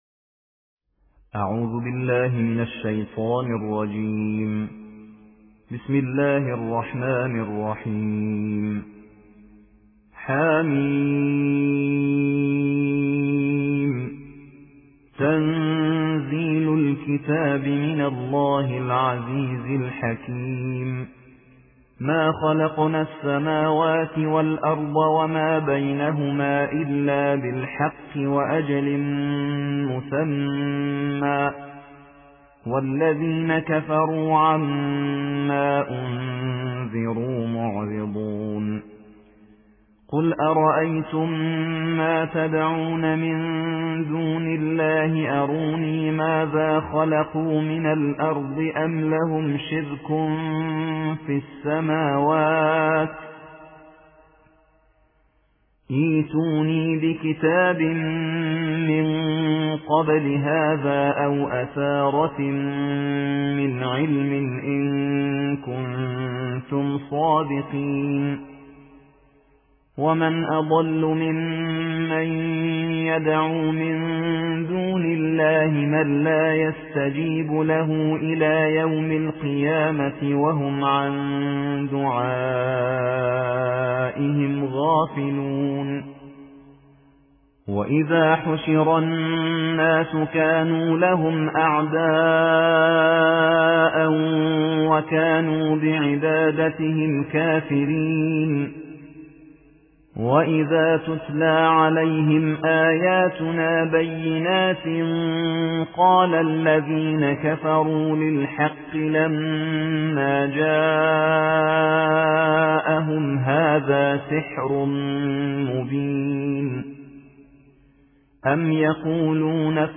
ترتیل جزء بیست‌وششم قرآن